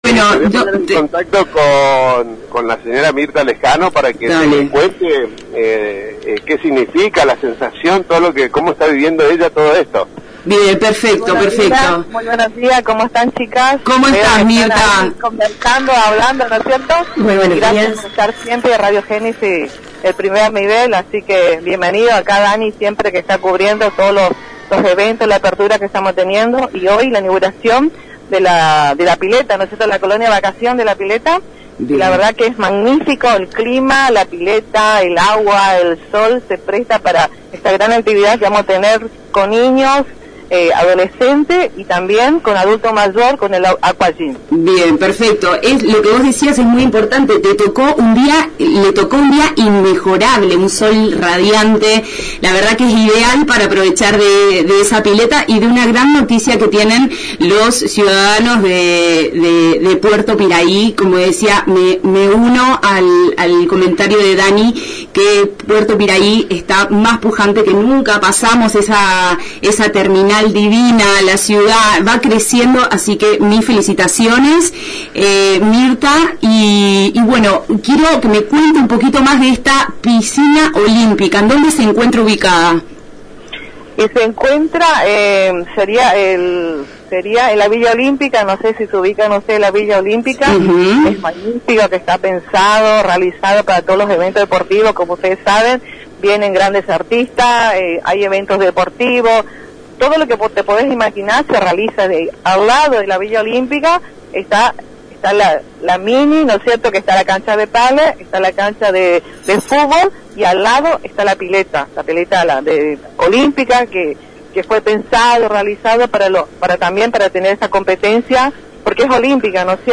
En diálogo con ANG y Multimedios Gènesis contó que por la mañana una profesional brindará clases para adultos mayores, y por la tarde otra profesional estará a cargo de niños y adolescentes.